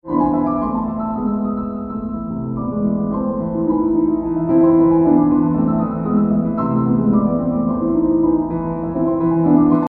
Then comes this glittery passage to close the exposition, I love this part: it has no real melody, but it is very beautiful nevertheless. It’s actually a canon.